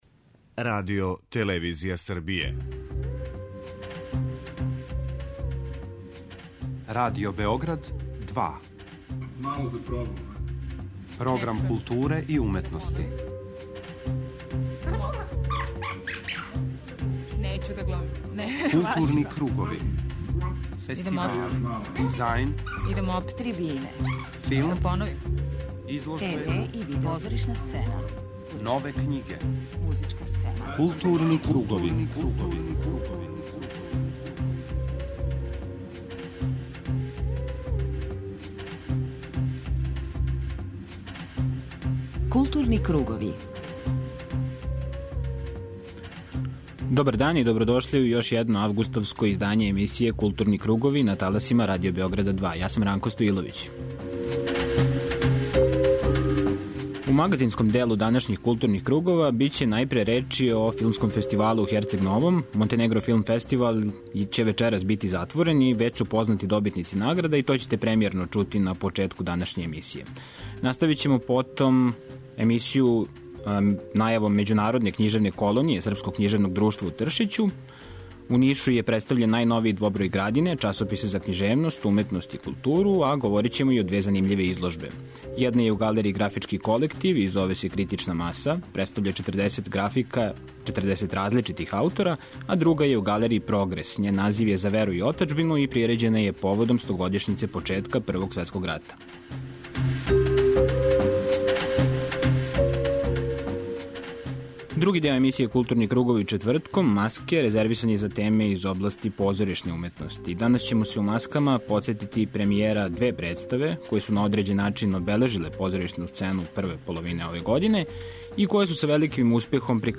преузми : 53.68 MB Културни кругови Autor: Група аутора Централна културно-уметничка емисија Радио Београда 2.